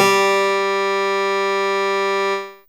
HChordGG3.wav